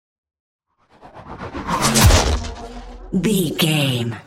Chopper whoosh to hit engine
Sound Effects
dark
futuristic
intense
tension
woosh to hit